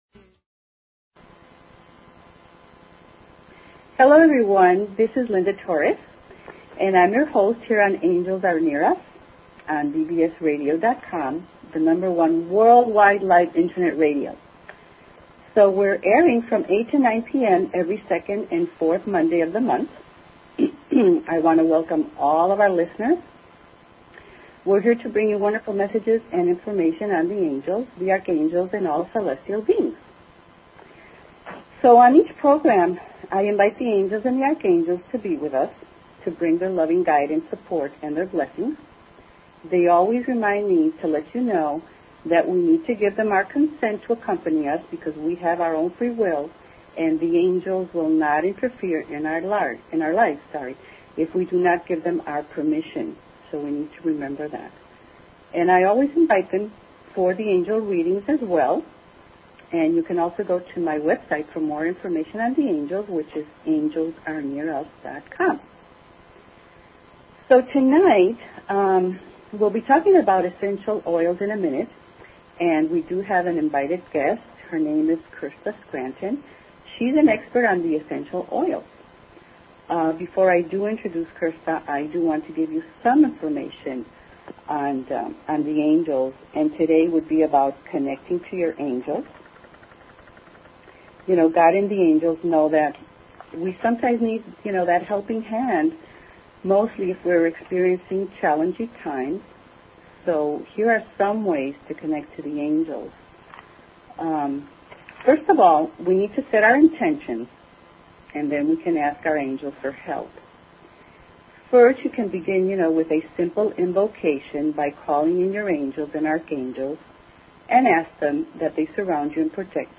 Talk Show Episode, Audio Podcast, Angels_Are_Near_Us and Courtesy of BBS Radio on , show guests , about , categorized as
The last 30 minutes of the show the phone lines will be open for questions and Angel readings.